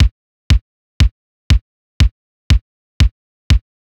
DAW ist Cubase 5.5.3, Rechner ein Dual-Core-Läppie, Audio ist die interne Soundkarte mit ASIO4ALL Ich habe ein 32-taktiges Bass-Drum-Pattern mit Groove-Agent One erzeugt, keinerlei Effekte genutzt und dieses in Mono als WAV (16/44.1) gerendert.
Zu den Audios: "Timingtest1" ist der pure Sound der Bassdrum (also nur "1 mal)", "Timingtest2" der Sound der Bassdrum auf allen 16 Spuren (also 16-fach gestackt).